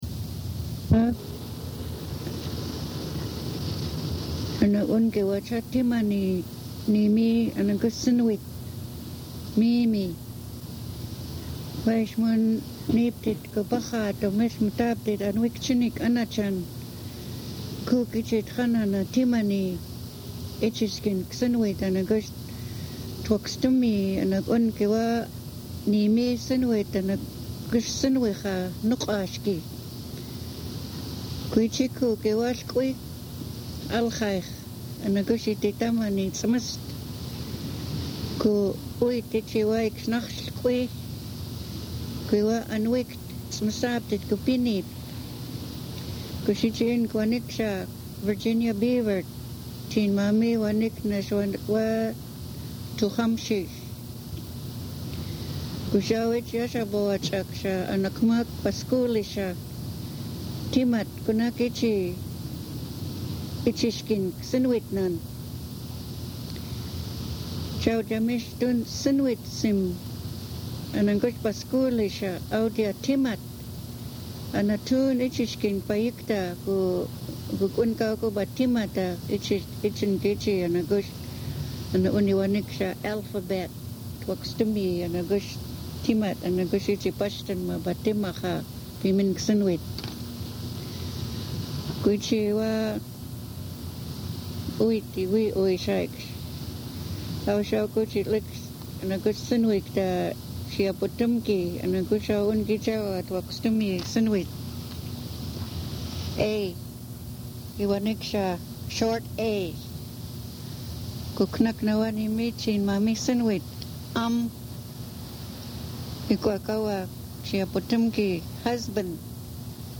yakama-abc-tape.mp3